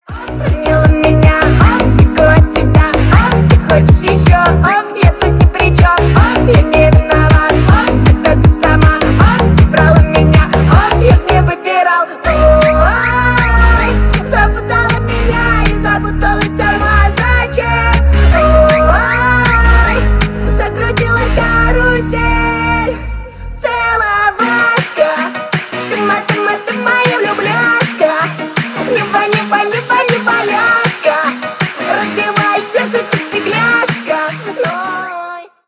Поп Музыка
весёлые # милые